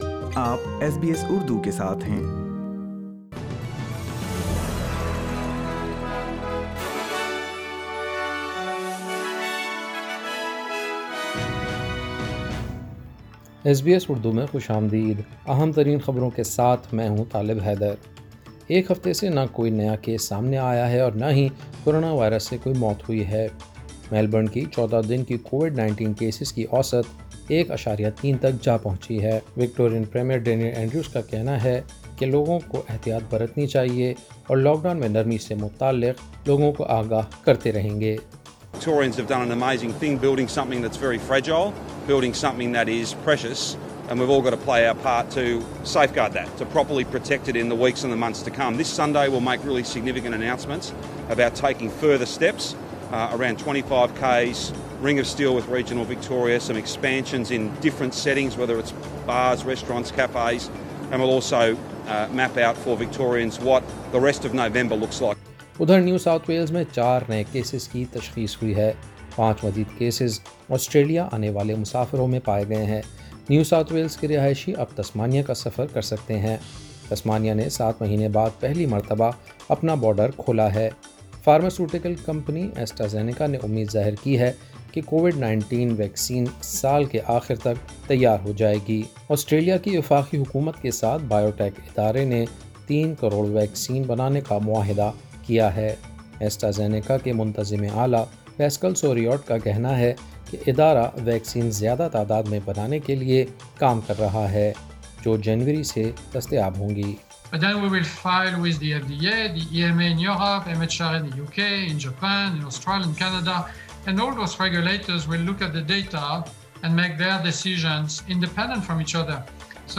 ایس بی ایس اردو خبریں 6 نومبر 2020